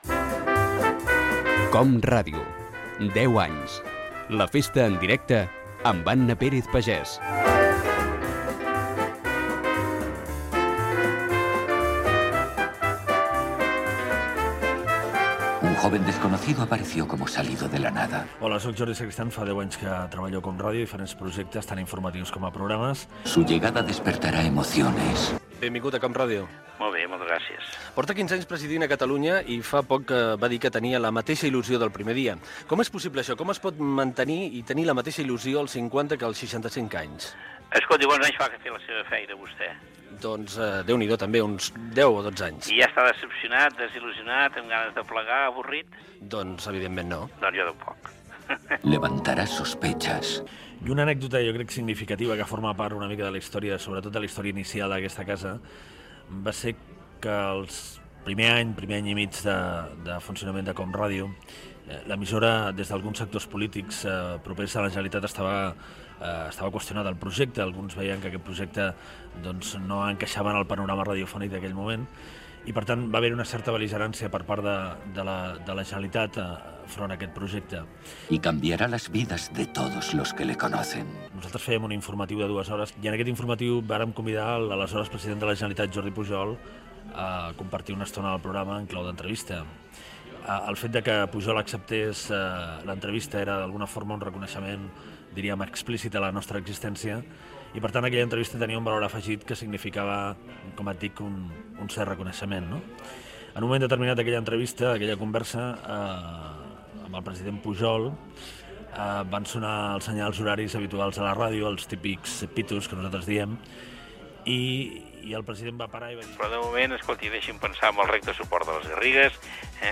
Indicatiu del programa i entrevista
Entreteniment
Fragment extret de l'arxiu sonor de COM Ràdio.